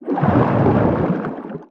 File:Sfx creature chelicerate swim fast 05.ogg: Difference between revisions
Sfx_creature_chelicerate_swim_fast_05.ogg